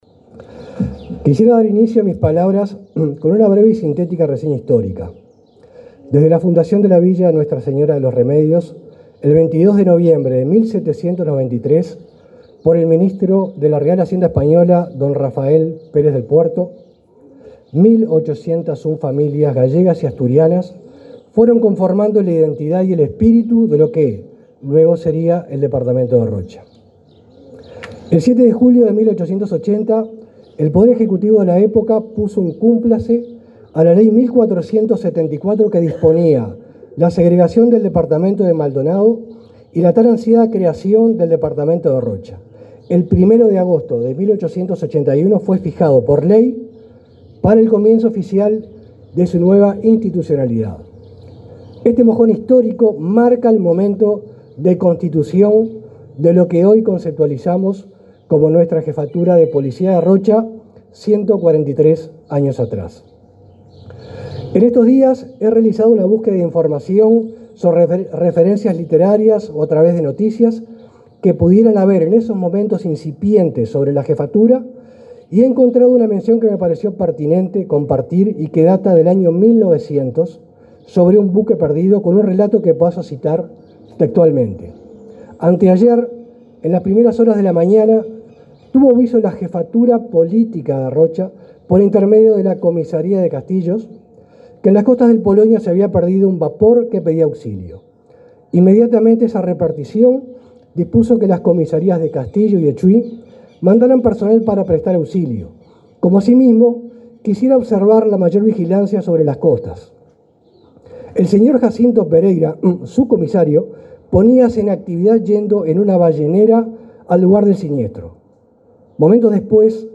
El titular de la Jefatura de Policía de Rocha, Claudio Correa, se expresó durante la ceremonia de celebración del 143.° aniversario de esa dependencia